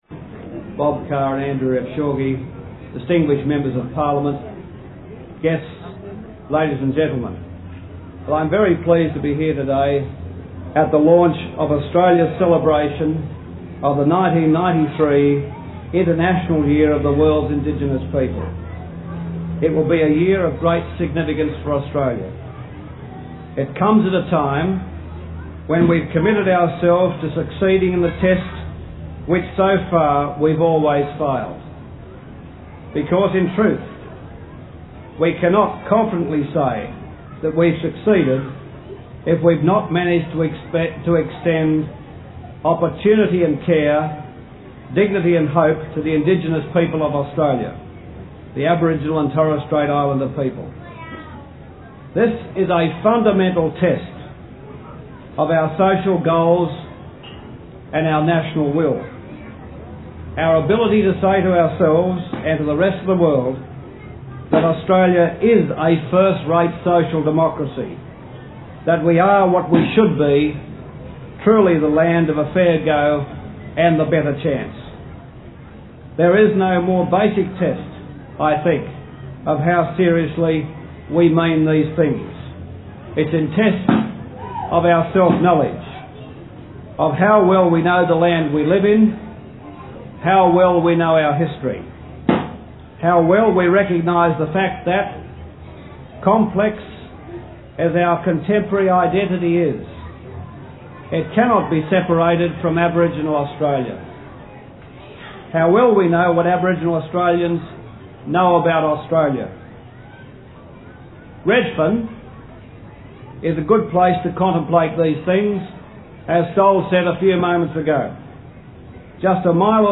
The Hon. Paul Keating Prime Minister of Australia gave this address in Redfern NSW to launch the International Year of the World's Indigenous People.